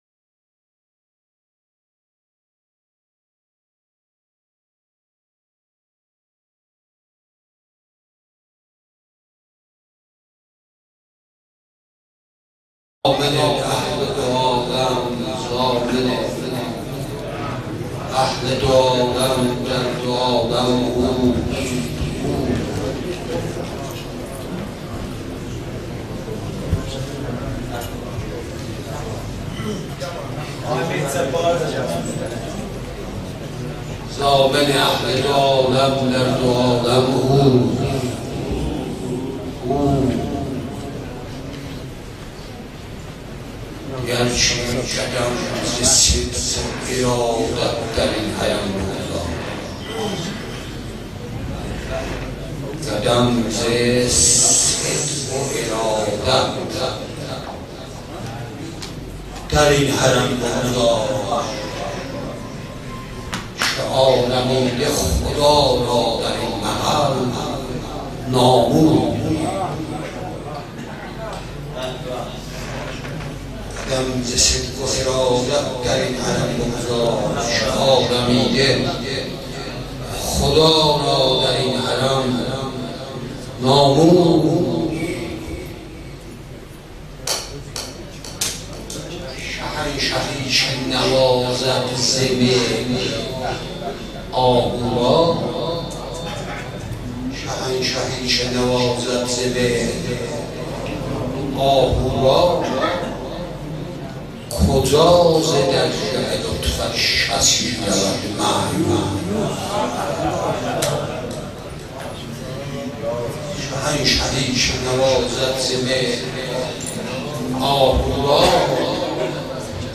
مدح
ایام ولادت با سعادت حضرت علی بن موسی الرضا علیه السلام
هیأت شاهزاده حضرت علی اصغر علیه السلام